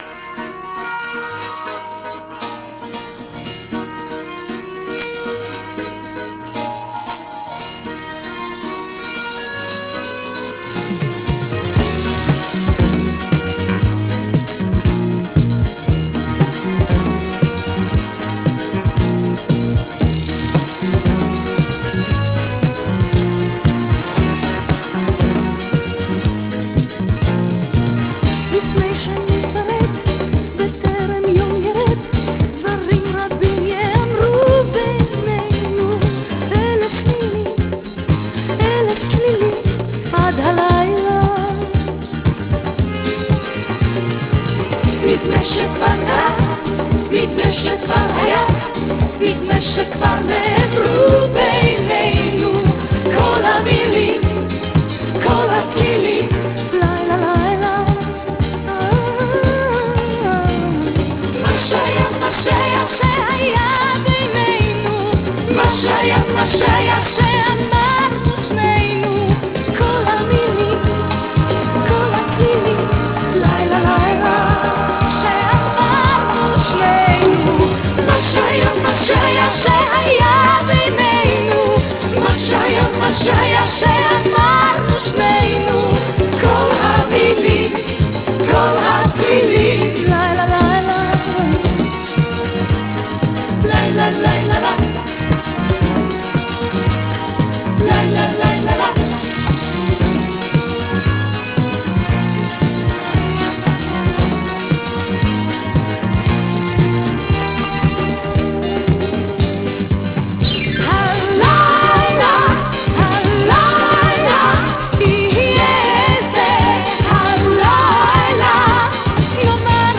Dublin, Ireland